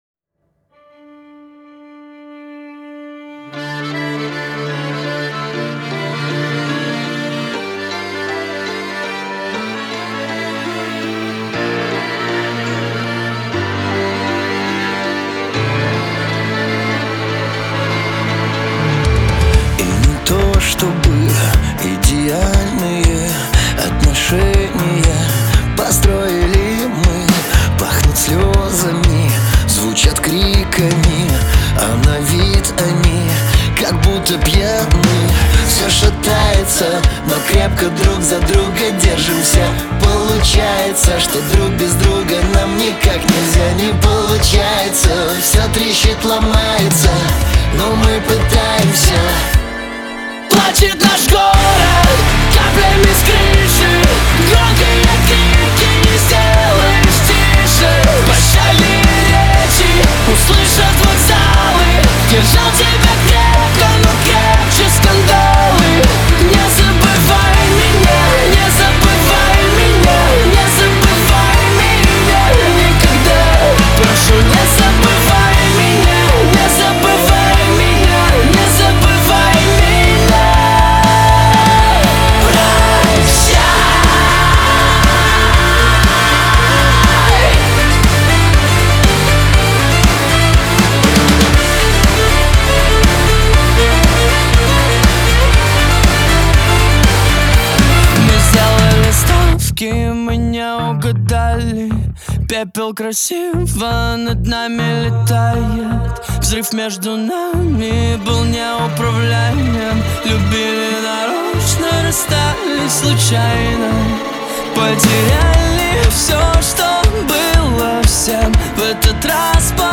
pop
грусть
эстрада